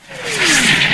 mortarWhistle.ogg